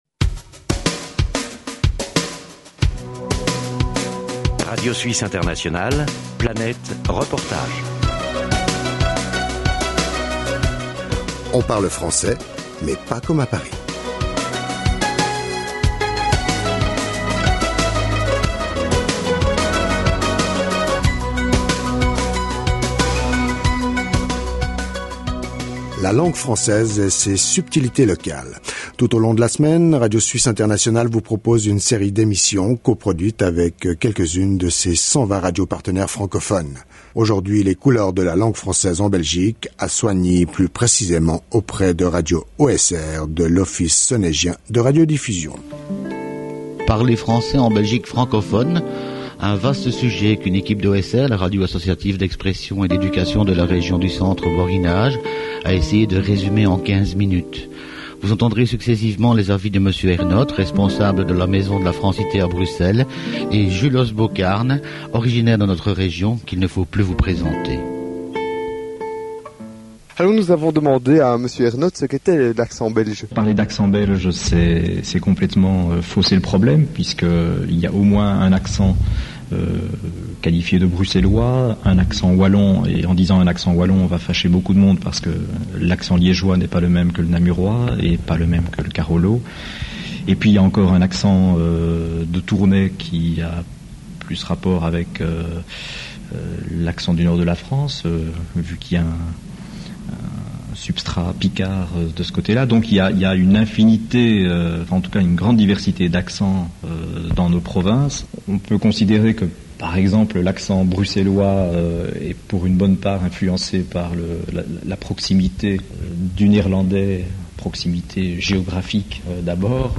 (Archives Radio Suisse Internationale, 1997, série «Le français parlé»).